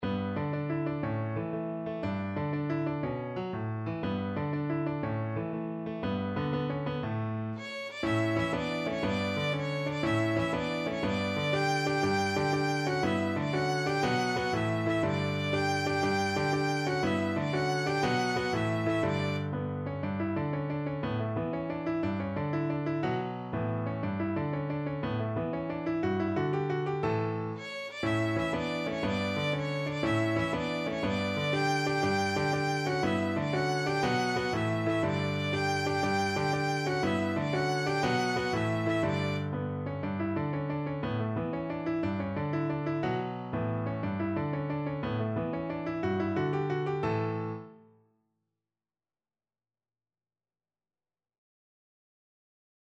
Classical Halle, Adam de la J'ai encore une tel paste from Le jeu de Robin et Marion Violin version
Violin
6/8 (View more 6/8 Music)
D major (Sounding Pitch) (View more D major Music for Violin )
With energy .=c.120
Classical (View more Classical Violin Music)